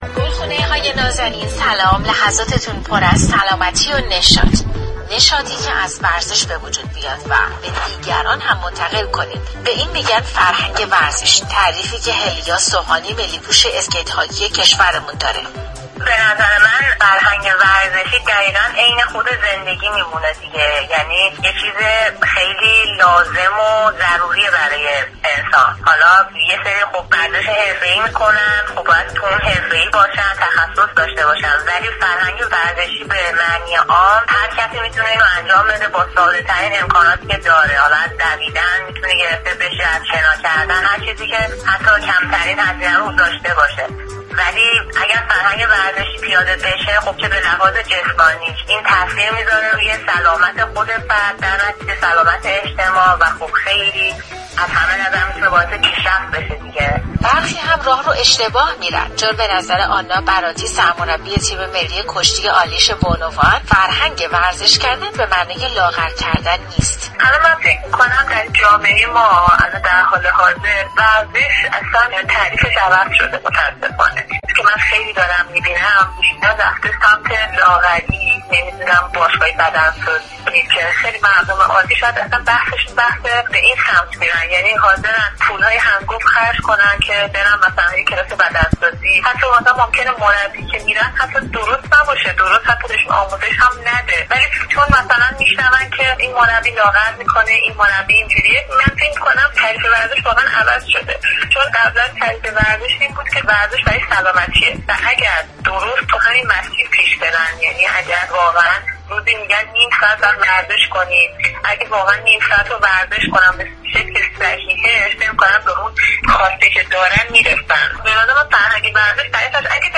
شما می توانید از طریق فایل صوتی پیوست شنونده این گفتگو با برنامه گلخونه رادیو ورزش بطور كامل باشید.